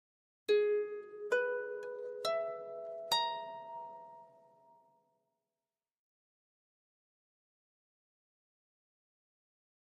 Harp, Very Slow Reverberant Arpeggio, Type 2